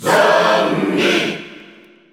Category:Crowd cheers (SSBU) You cannot overwrite this file.
Zombie_Cheer_Dutch,_English,_&_French_NTSC_SSBU.ogg